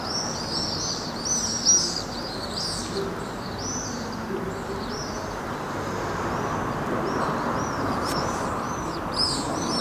Observação BirdNET - Andorinhão-pálido - 2022-04-24 08:33:34
Andorinhão-pálido observado com o BirdNET app. 2022-04-24 08:33:34 em Lisboa